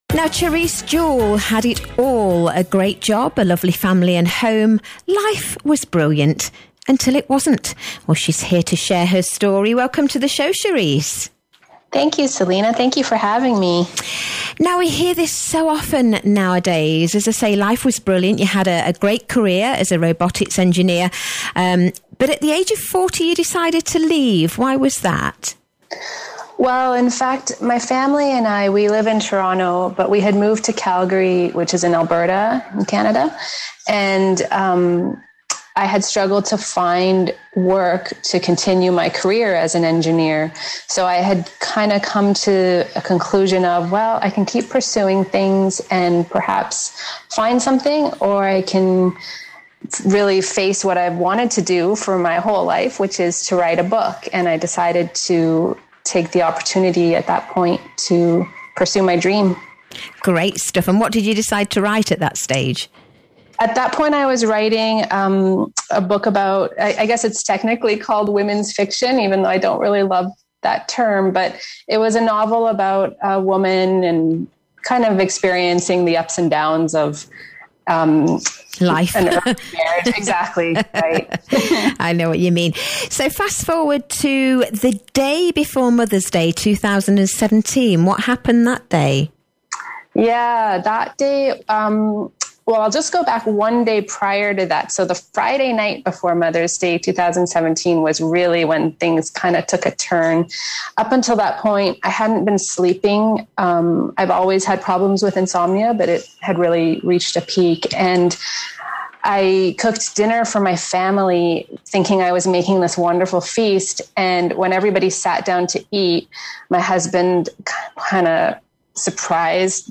Talk Radio Europe Interview
tre-interview.mp3